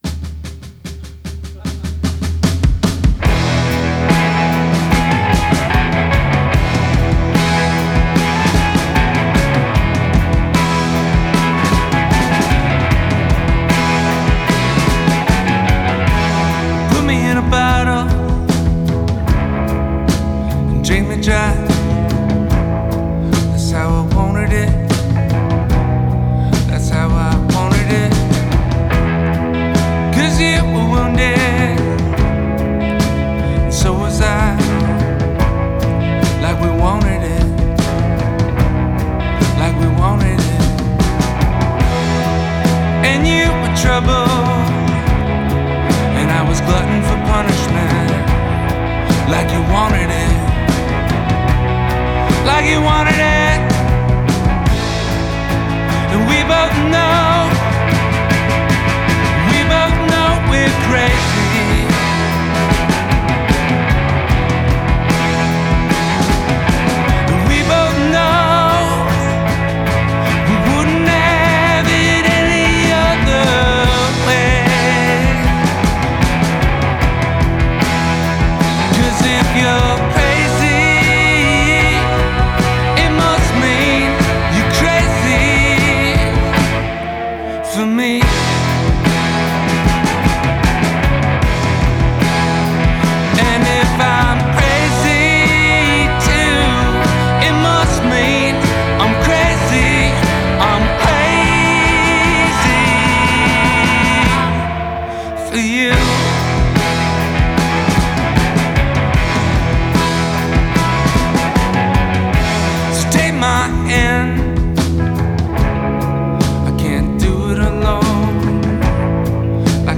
a by-turns seductive and piledriving sonic assault